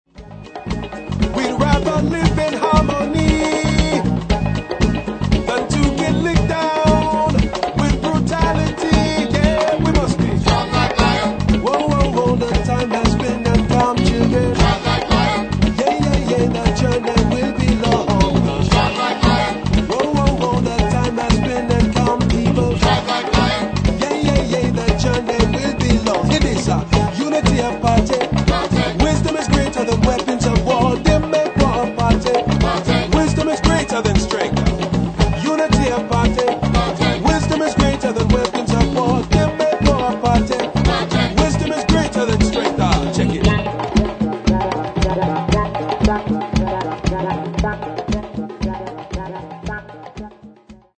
Hip Hop, Reggae, Funk, Latin Music, World Music
The result feels like a live CD, minus crowd noise.
funk-infected guitar work